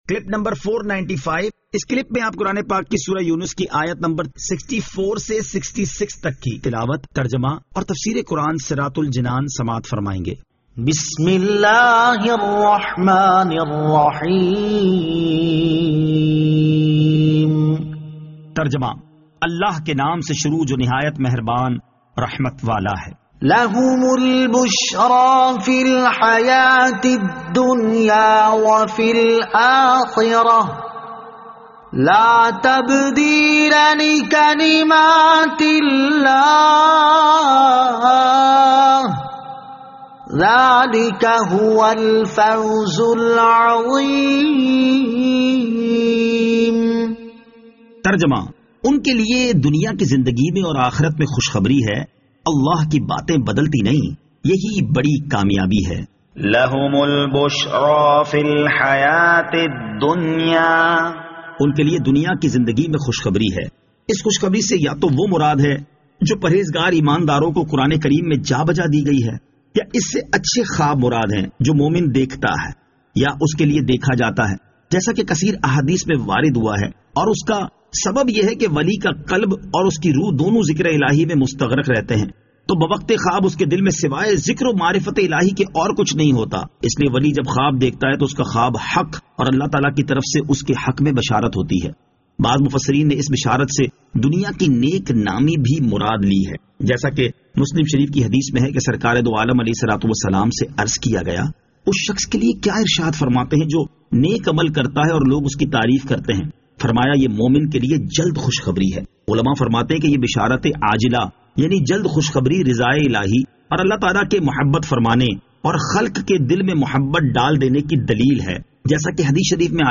Surah Yunus Ayat 64 To 66 Tilawat , Tarjama , Tafseer